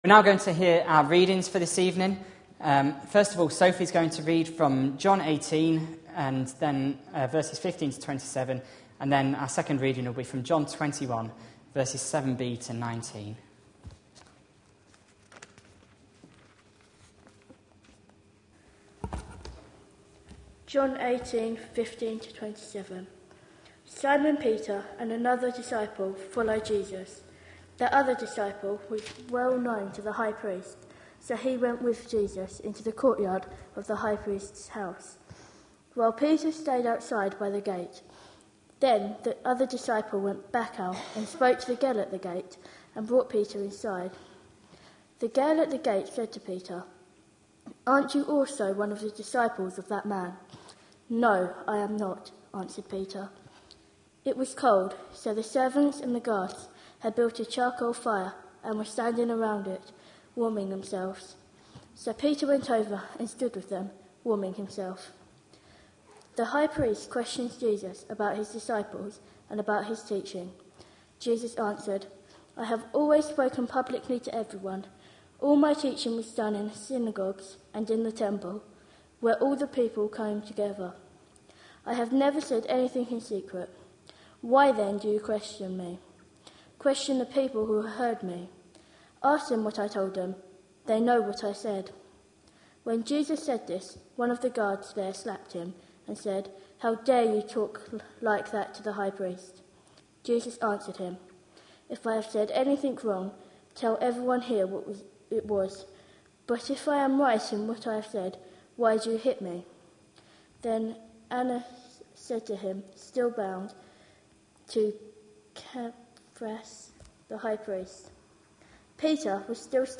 A sermon preached on 18th March, 2012, as part of our Looking For Love (6pm Series) series.